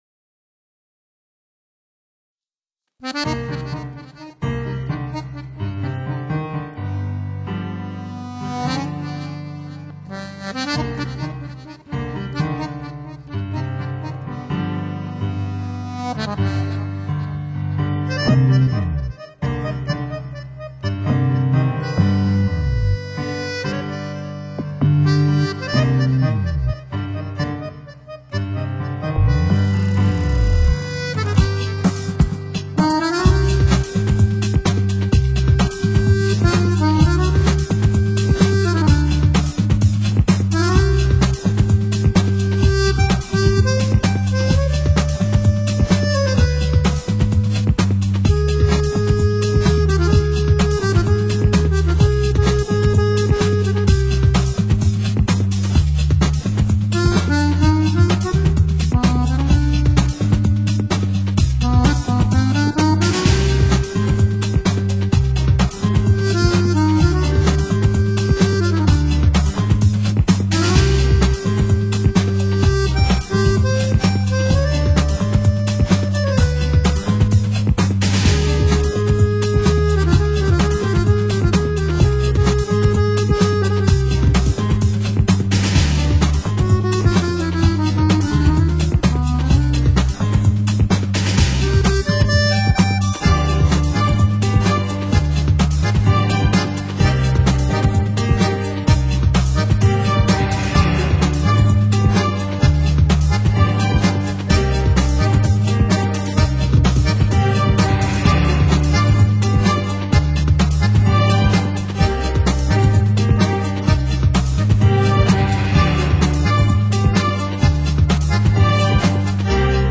手风琴Tango与爵士乐、电音音乐迸发的新音乐火花